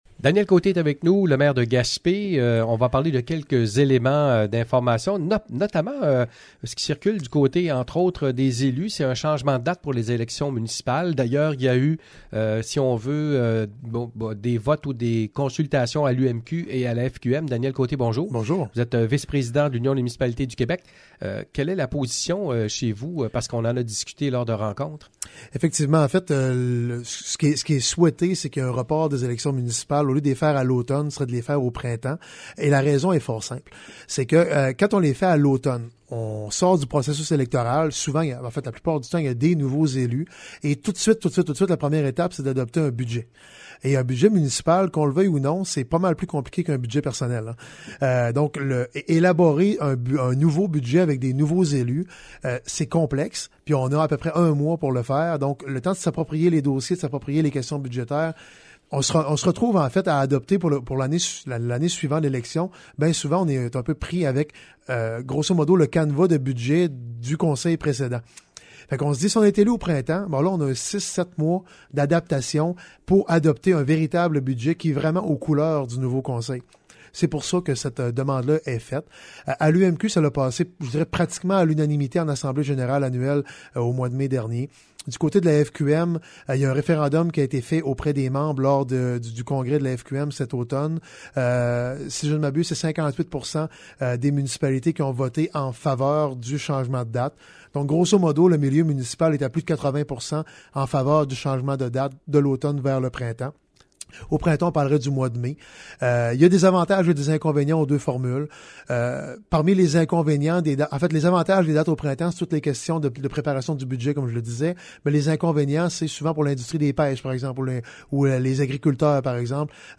Entrevue avec Daniel Côté